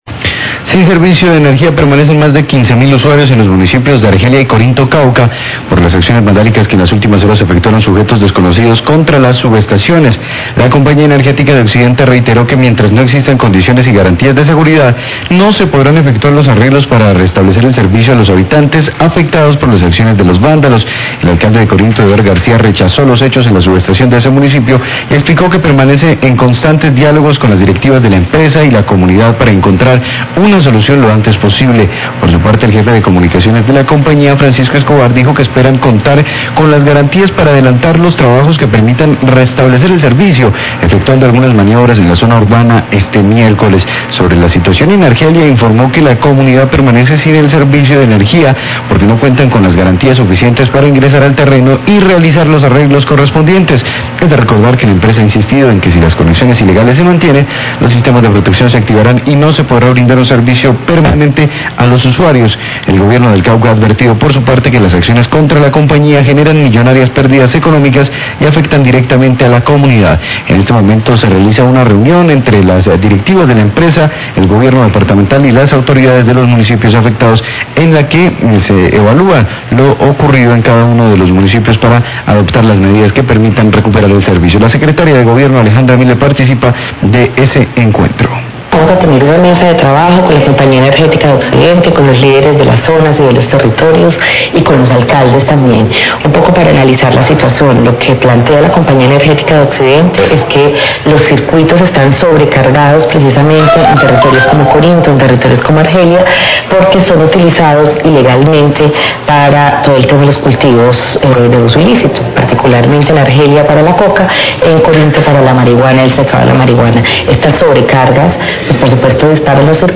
Radio
Declaraciones de Alejandra Miller, Secretaria de Gobierno del Cauca.